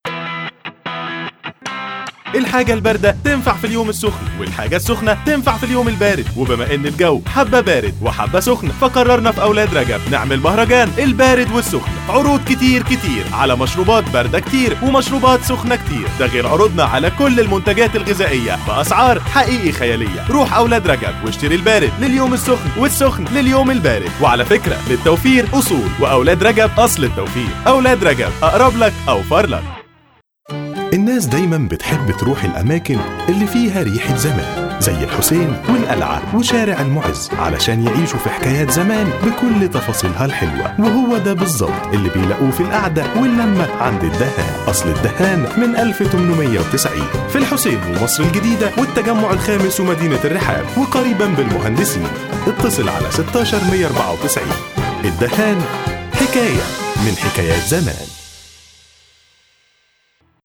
Mısır Arapçası Seslendirme
Erkek Ses